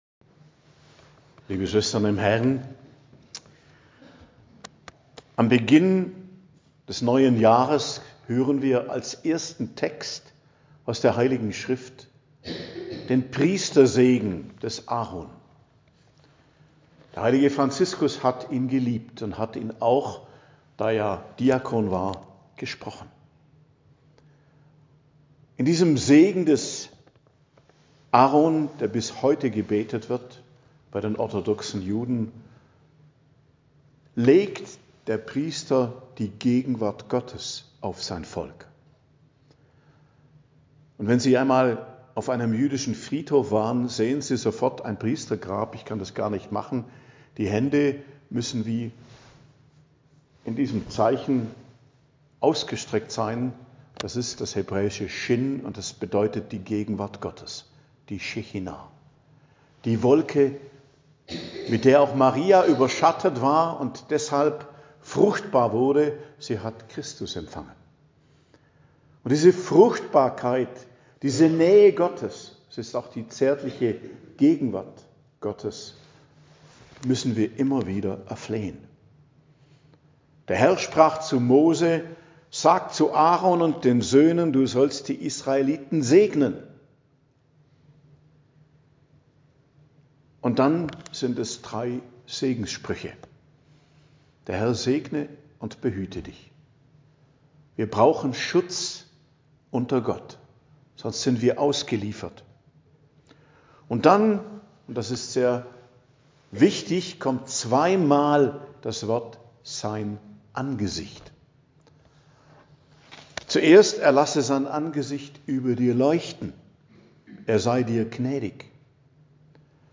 Predigt an Neujahr, Hochfest der Gottesmutter Maria, 1.01.2026